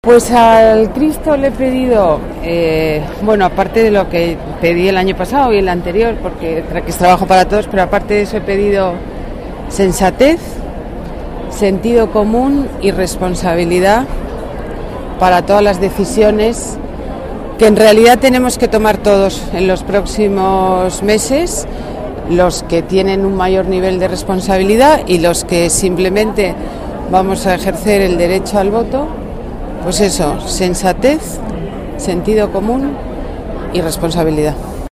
Nueva ventana:Declaraciones alcaldesa Cristo Medinaceli